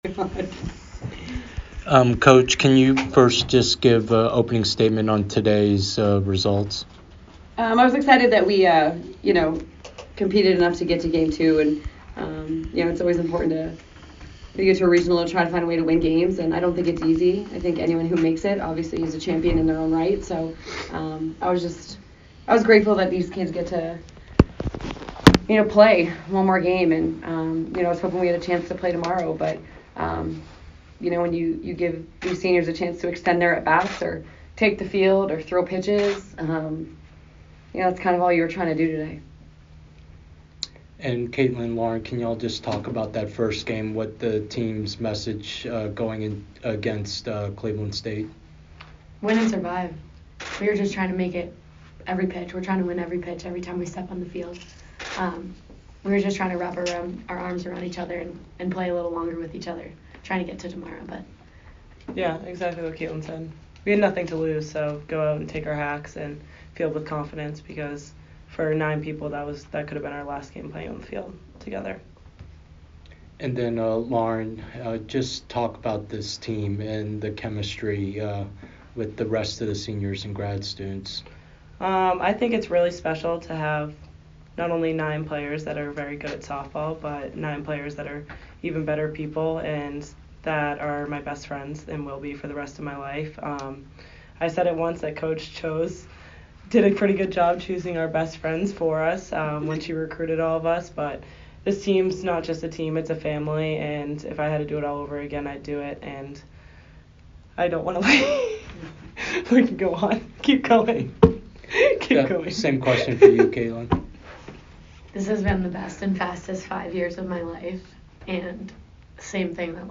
Softball / NCAA Regional Day 2 Postgame Interview